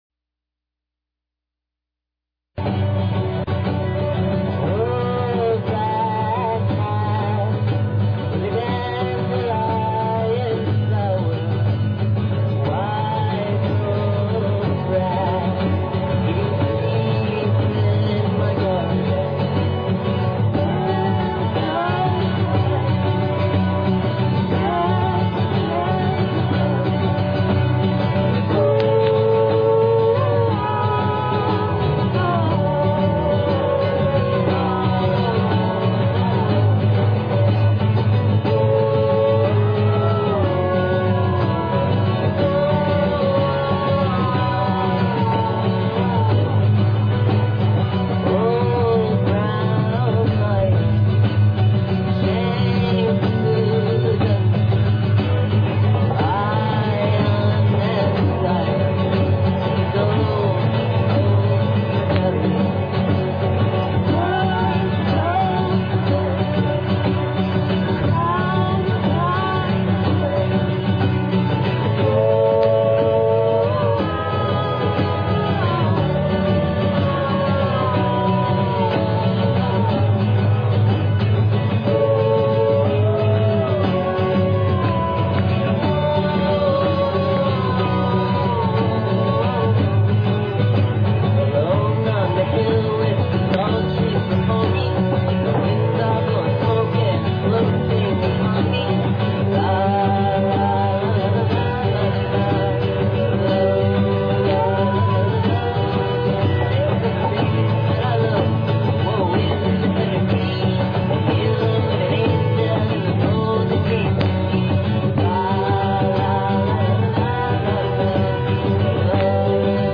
Live recording